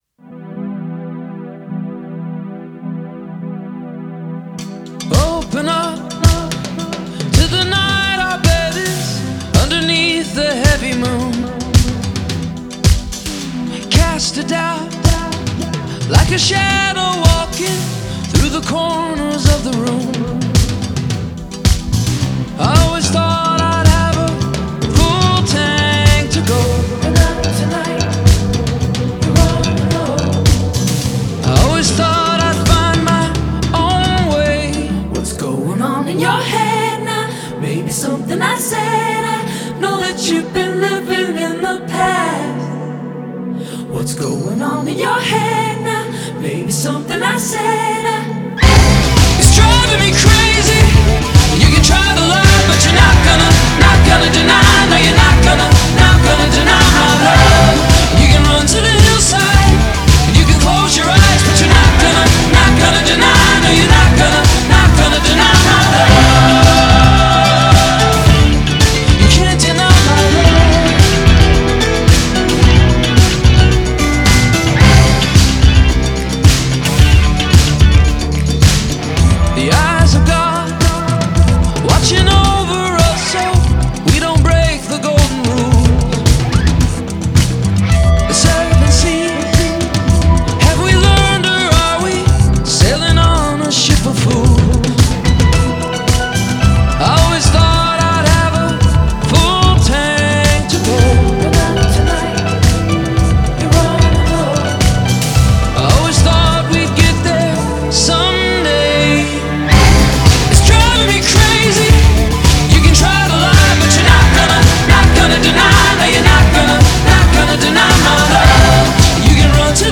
Genre: Pop Rock, Indie Rock